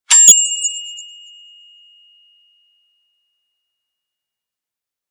Typewriter Ding Bell Sound Button: Unblocked Meme Soundboard
Play the iconic Typewriter Ding Bell sound button for your meme soundboard!